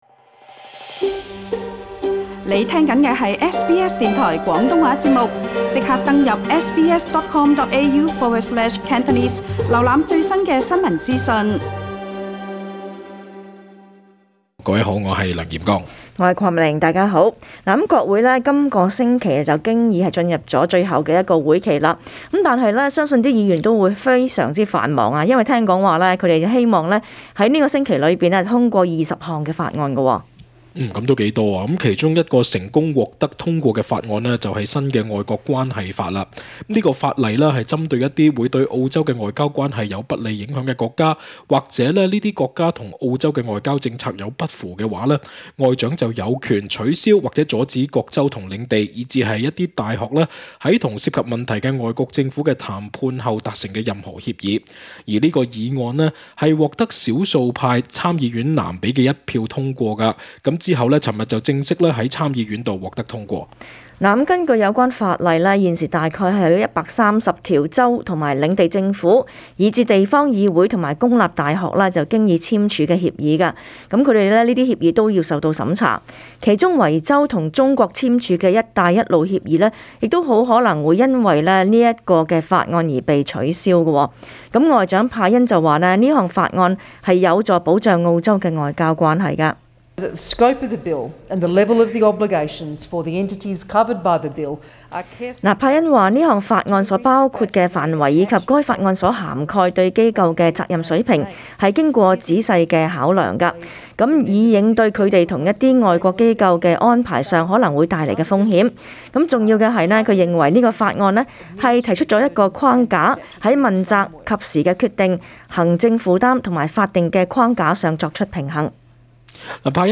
SBS廣東話節目